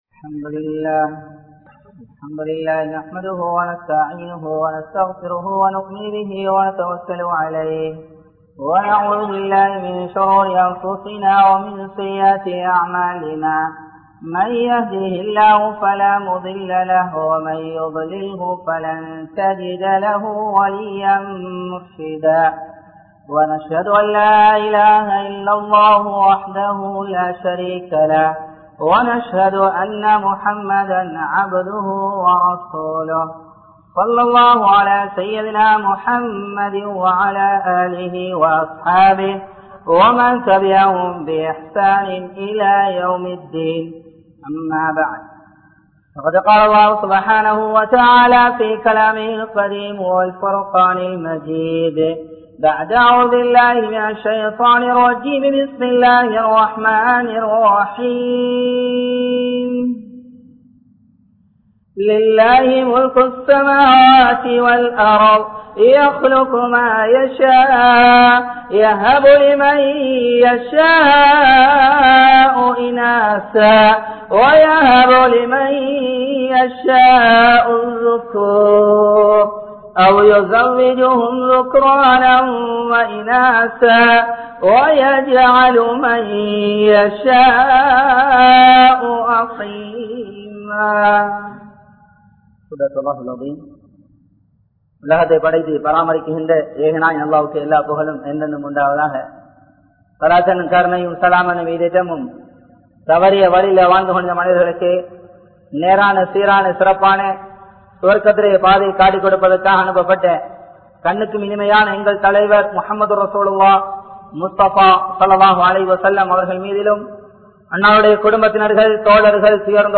Duties of Parents | Audio Bayans | All Ceylon Muslim Youth Community | Addalaichenai